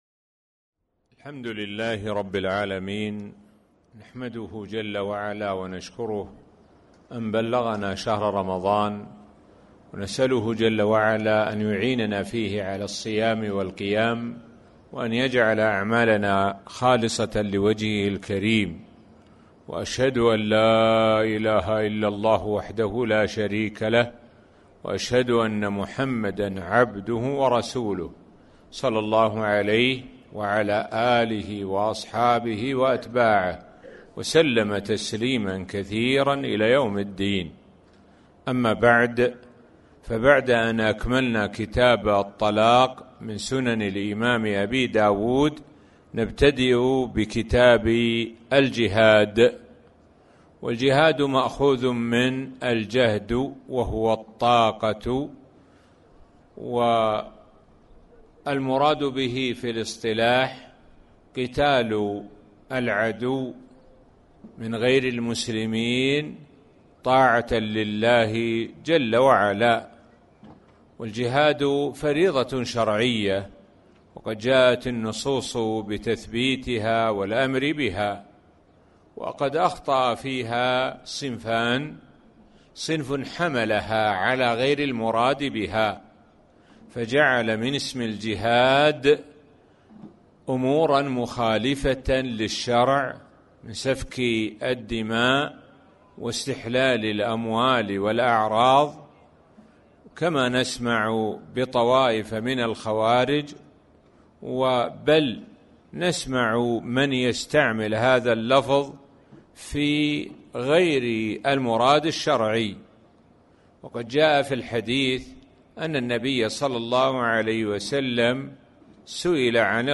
تاريخ النشر ١ رمضان ١٤٣٩ هـ المكان: المسجد الحرام الشيخ: معالي الشيخ د. سعد بن ناصر الشثري معالي الشيخ د. سعد بن ناصر الشثري كتاب الجهاد